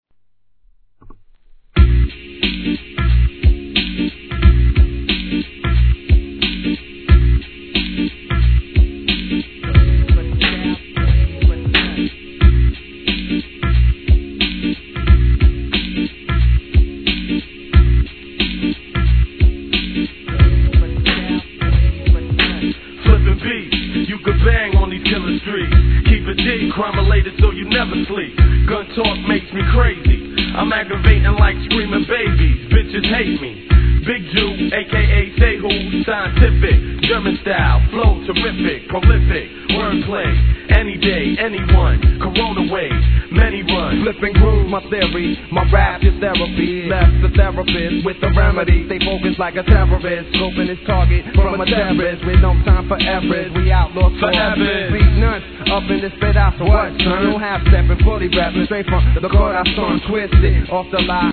HIP HOP/R&B
CHEEPながら中毒性あるONE LOOPトラックがいかにも'90sの王道ヒップホップ!